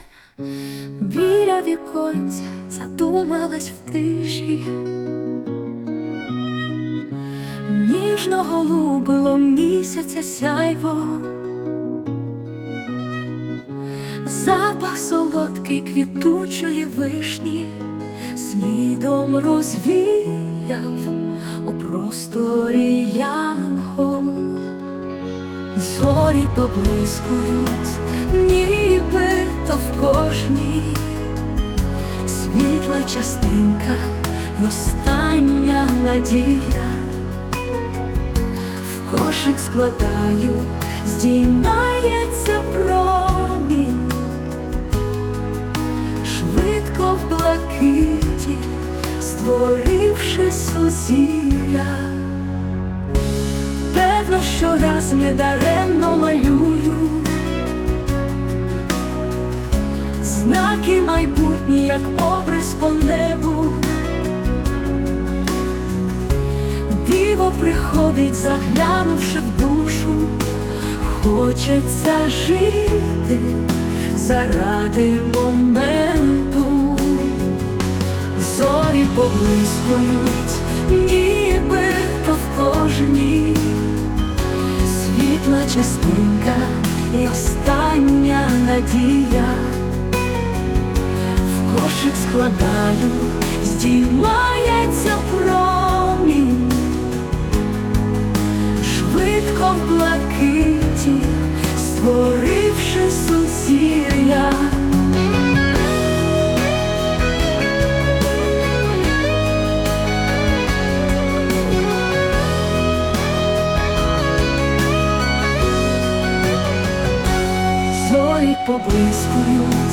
Музична композиція створена за допомогою ШІ
СТИЛЬОВІ ЖАНРИ: Ліричний